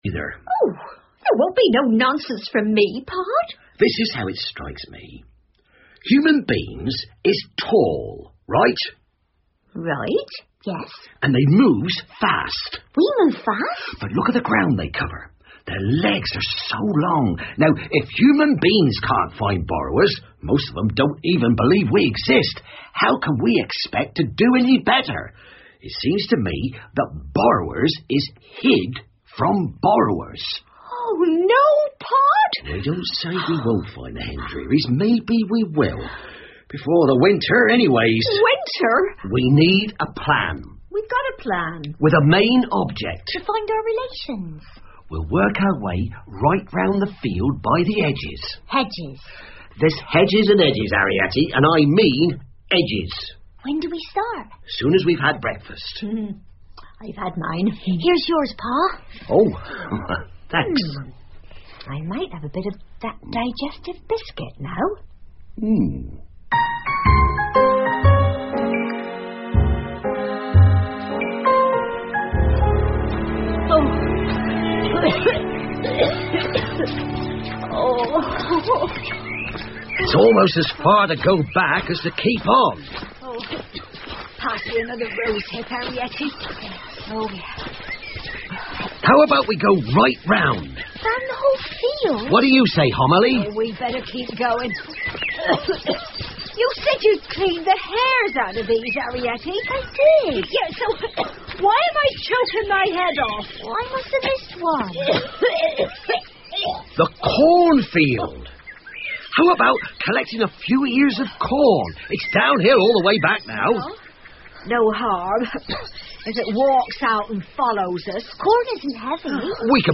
借东西的小人 The Borrowers 儿童广播剧 12 听力文件下载—在线英语听力室